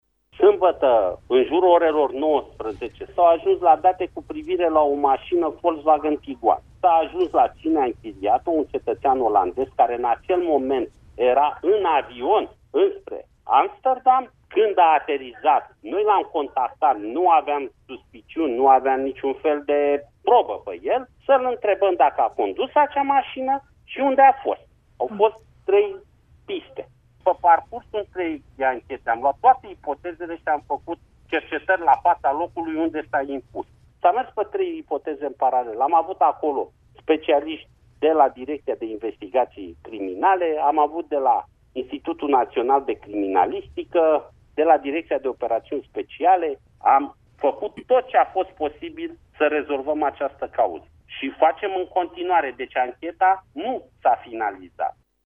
Şeful Poliţiei Române a explicat că sâmbătă autorităţile române nu aveau niciun temei pentru a-l împiedica pe cetăţeanul olandez să iasă din România şi a ţinut să sublinieze că oamenii din subordinea sa au făcut tot ce era posibil pentru a afla ce s-a întâmplat cu fetiţa de 11 ani care a dispărut de acasă vinerea trecută: